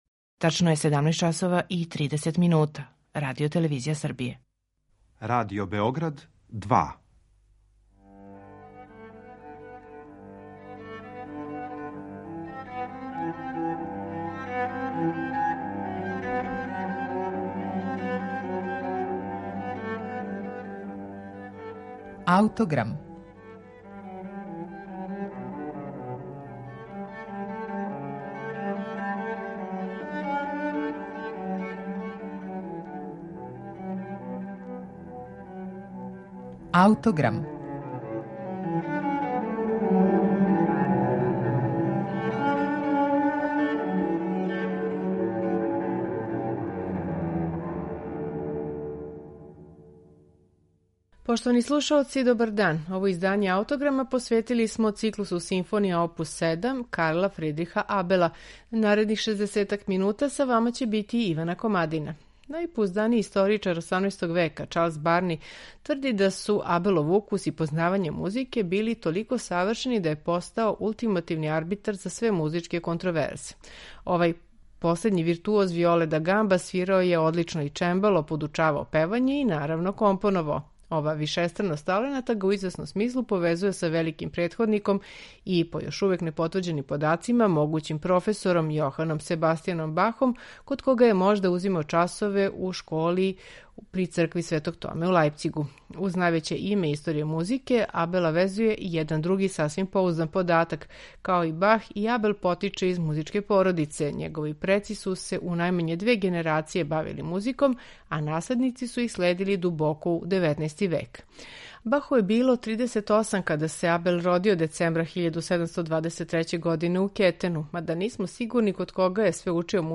Симфонија је у то доба била релативно нов жанр, са формом која још увек није била до краја дефинисана.